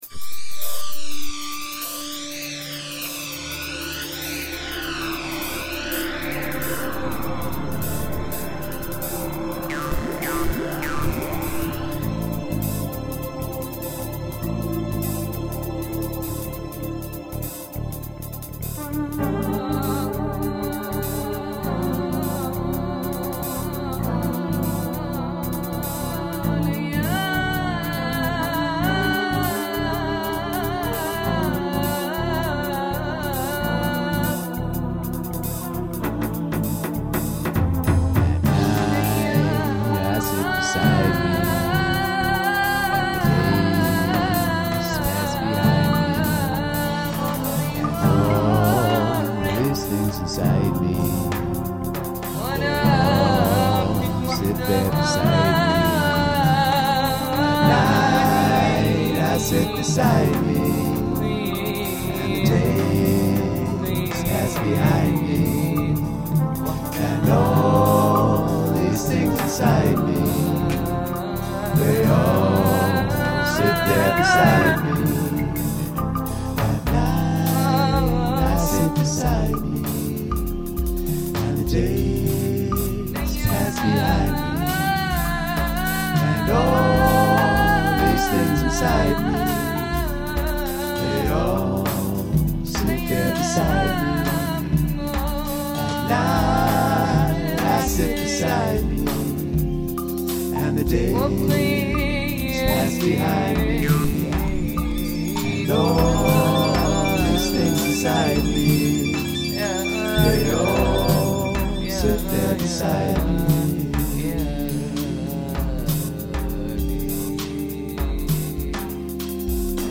Electronic/Sound Design
with vocals